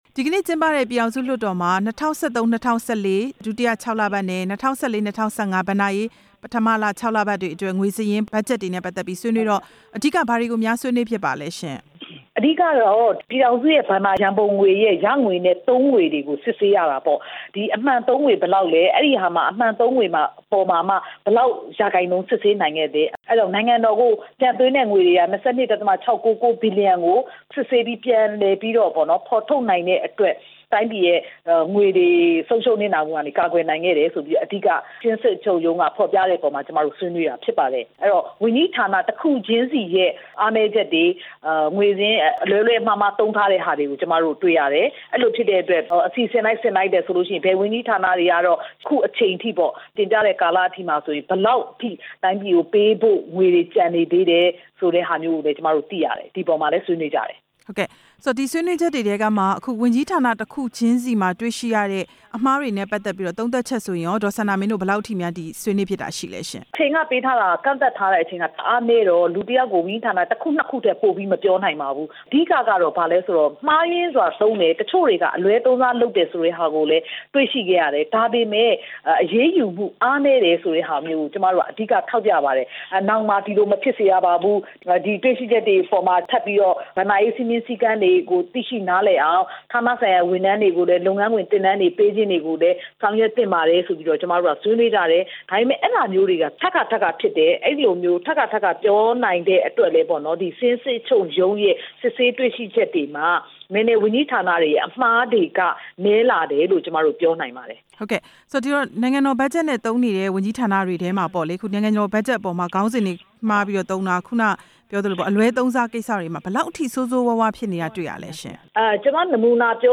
ဒေါ်စန္ဒာမင်းကို မေးမြန်းချက်